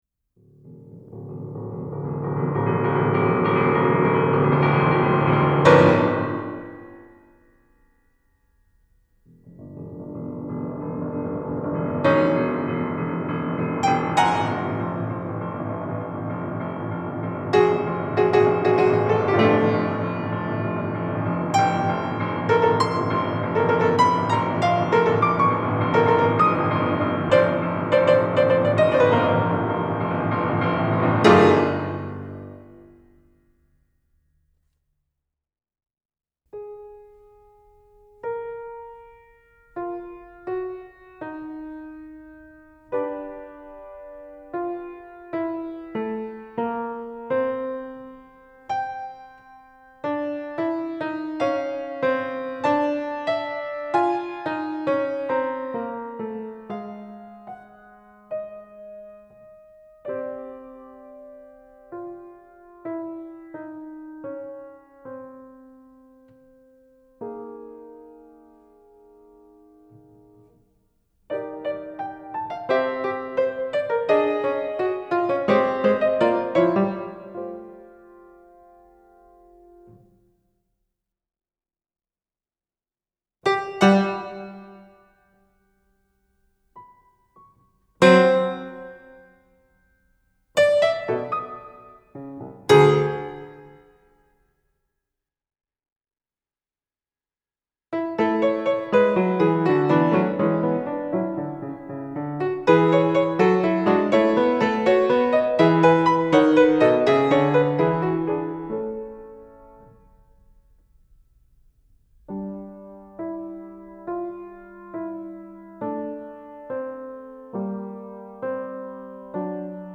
Retratos para piano